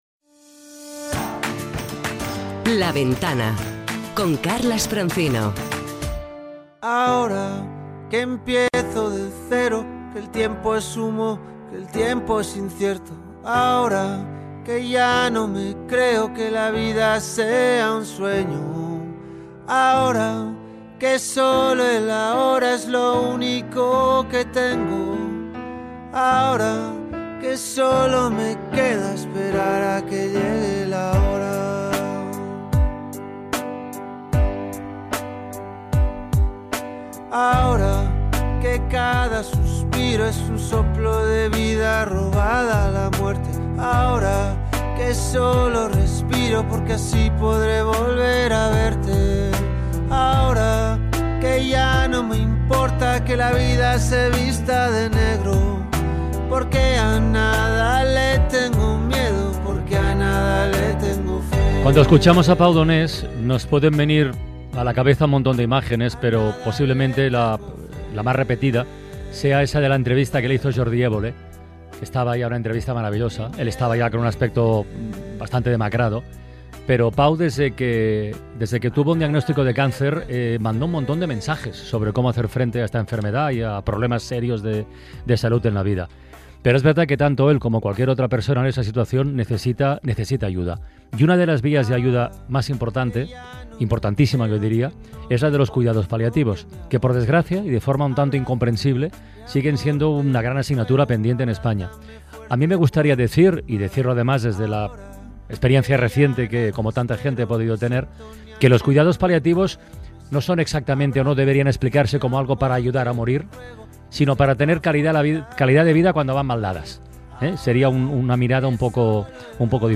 Ponemos nombre y voz a una paciente de cuidados paliativos